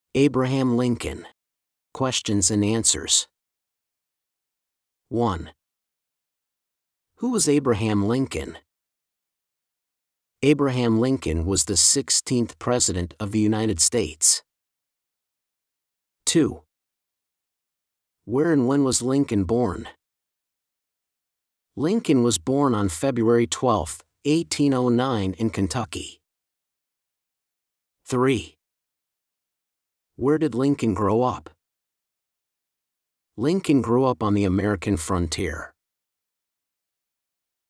Two MP3s and transcript: A narration about the event and a factual Q&A segment.
30PFTP-SAMPLE-Abraham-Lincoln-Questions-Answers.mp3